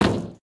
main Divergent / mods / Footsies / gamedata / sounds / material / human / step / metall03gr.ogg 6.1 KiB (Stored with Git LFS) Raw Permalink History
metall03gr.ogg